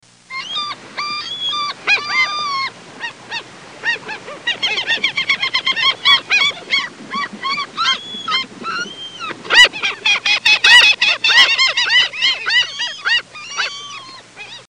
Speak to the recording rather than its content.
Tags: Travel Croatia Sounds of Croatia Destination Zagreb Croatia